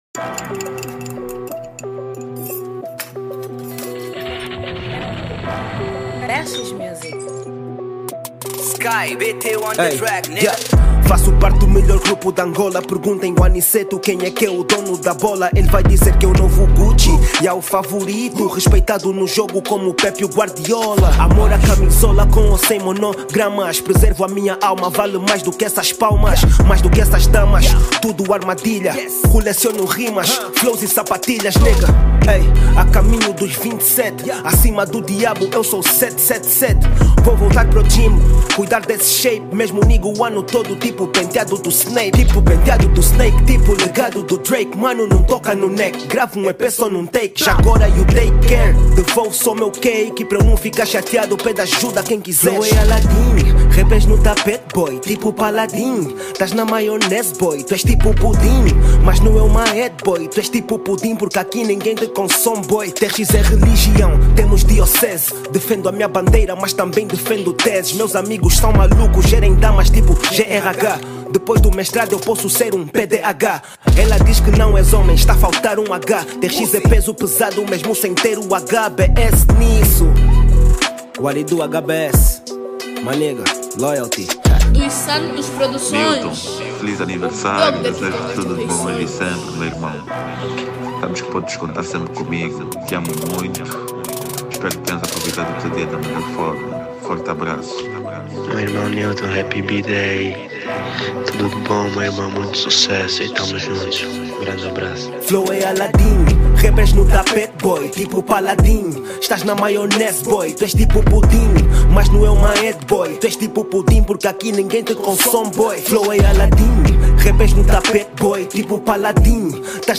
Categoria   Rap